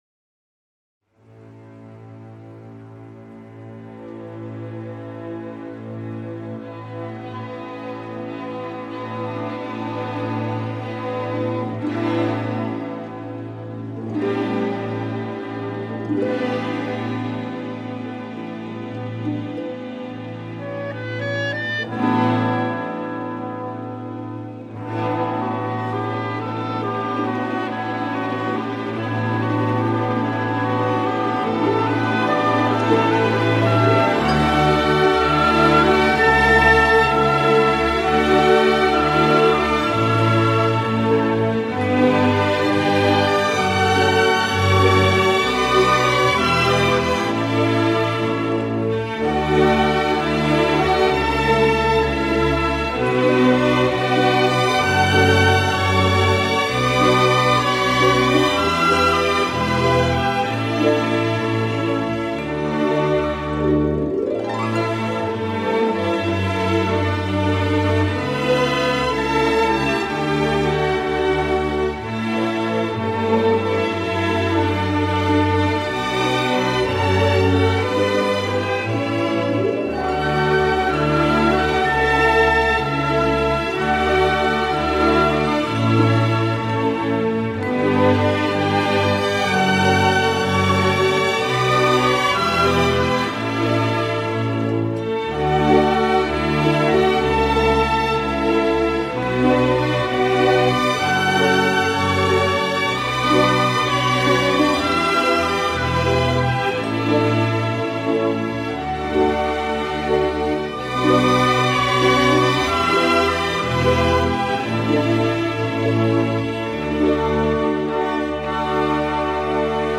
ce sont trois mondes à la fois lumineux et nocturnes
saisissant de noirceur